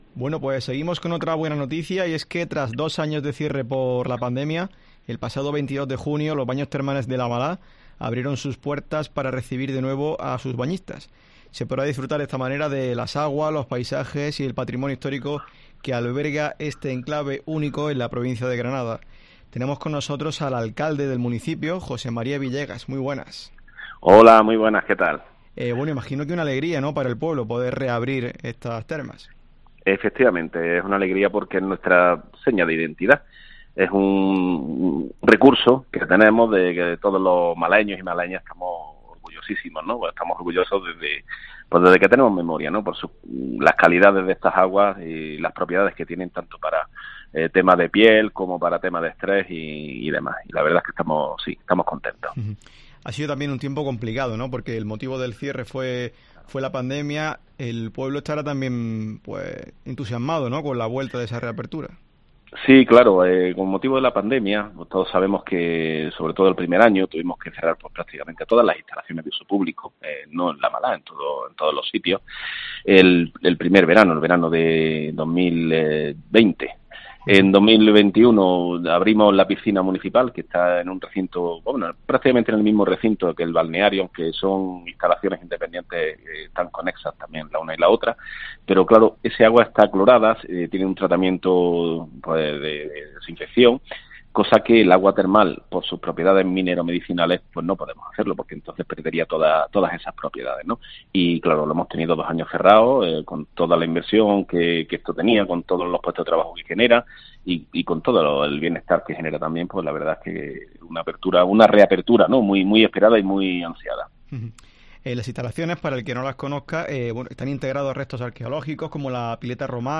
El alcalde del municipio, José María Villegas, ha pasado por los micrófonos de COPE Granada para comentar esta reapertura, que supondrá un auge turístico en La Malaha.